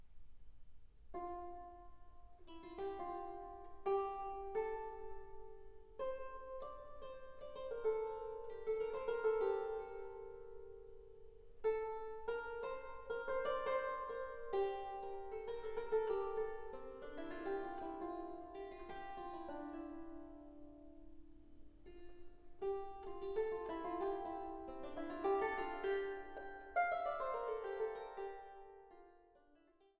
Viola da Gamba
Harfe